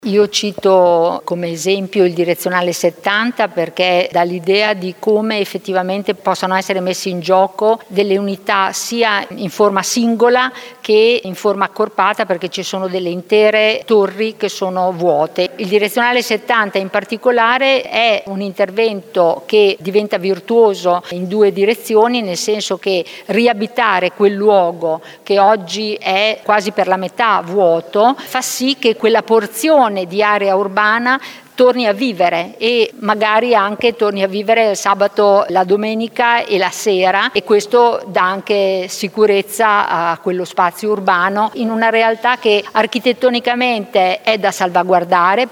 Un esempio su tutti gli spazi vuoti del Direzionale 70, come spiega Carla Ferrari, assessore all’Urbanistica: